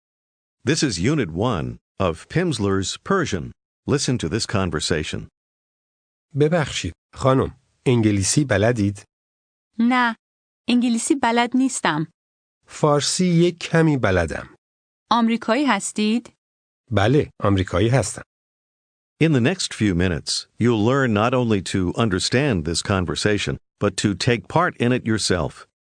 Аудио курс для самостоятельного изучения фарси (персидского языка).